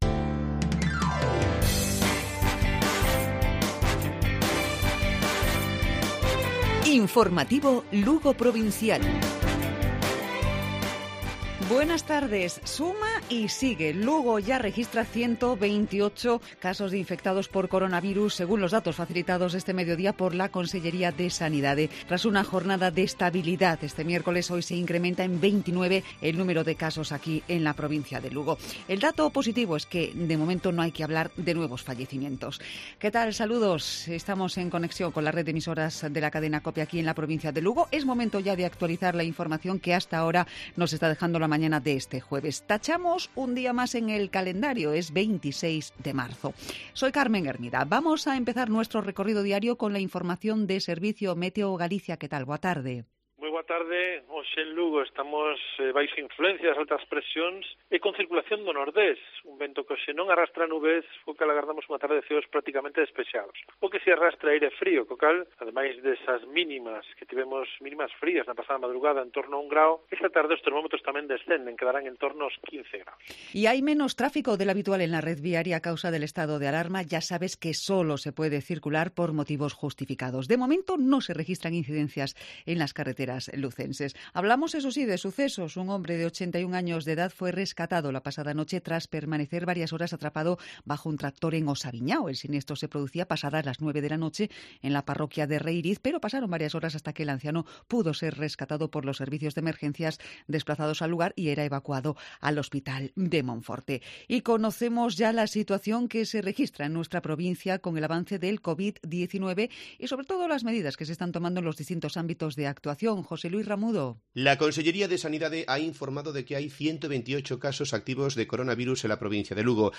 Informativo Provincial Cope.